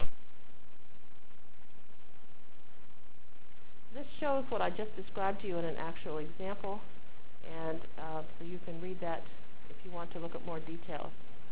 From Jan 29 Delivered Lecture for Course CPS616 -- Java Lecture 3 -- Exceptions Through Events CPS616 spring 1997 -- Jan 29 1997.